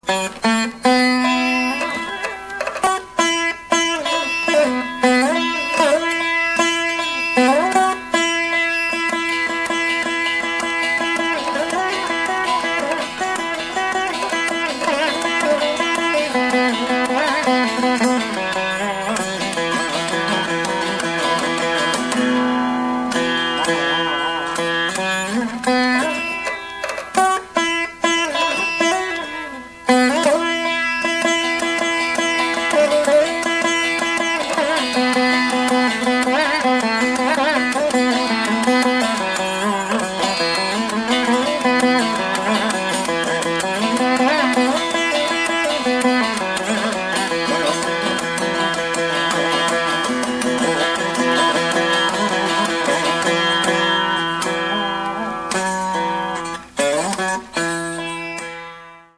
vīna